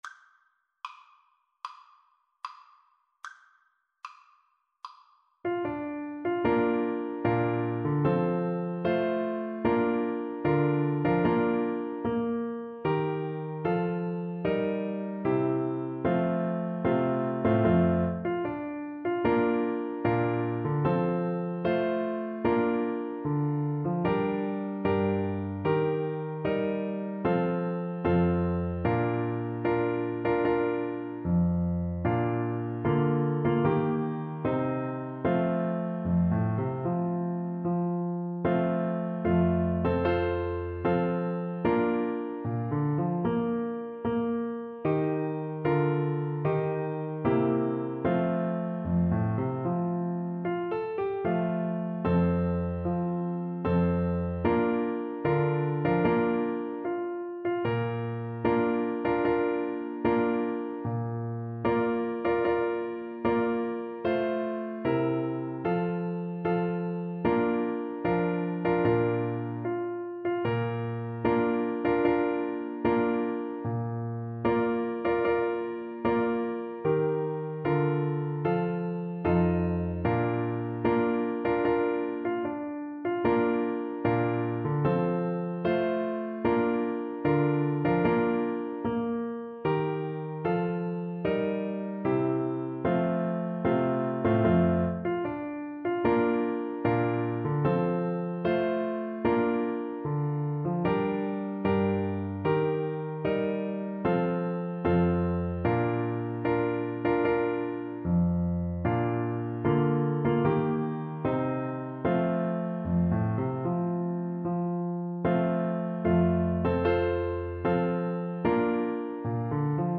March =c.100
D5-F6
4/4 (View more 4/4 Music)